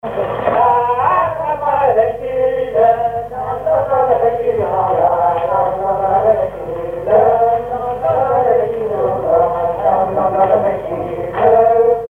Patois local
branle
Couplets à danser
chansons enregistrées sur un magnétophone à cassettes